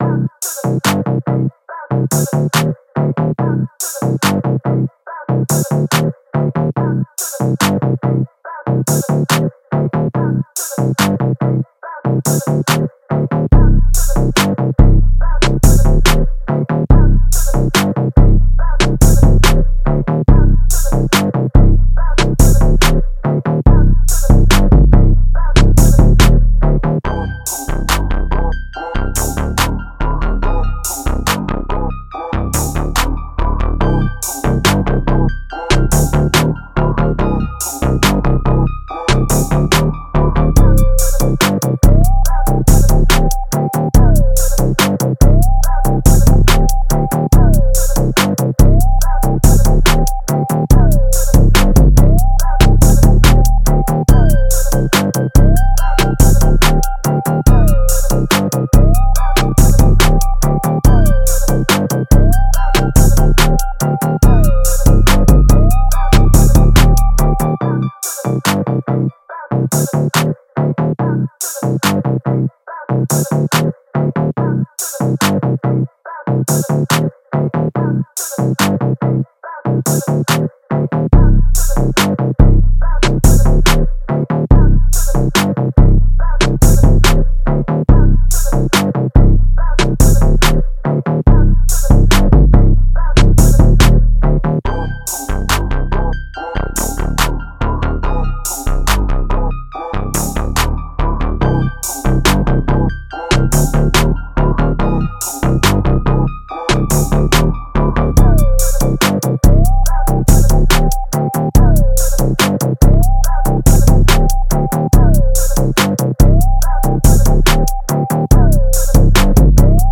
Hip Hop
C Minor